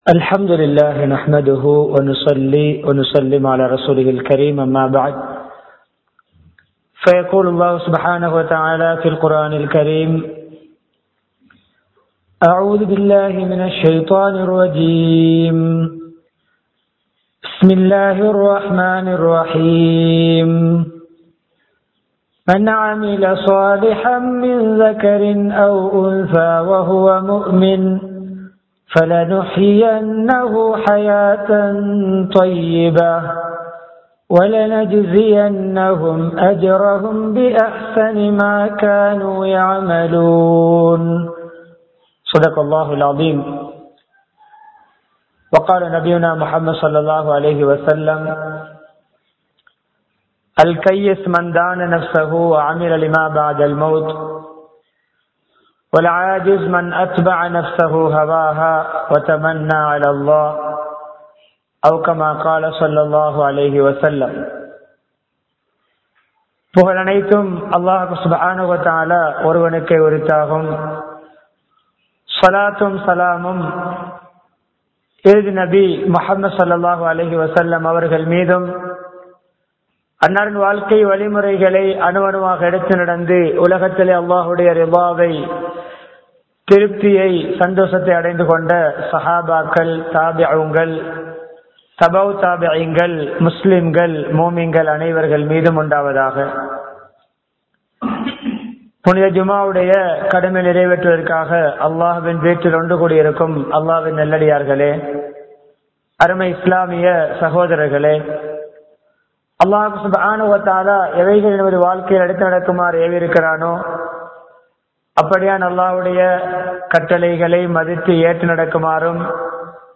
வாழ்க்கையில் வெற்றி வேண்டுமா? | Audio Bayans | All Ceylon Muslim Youth Community | Addalaichenai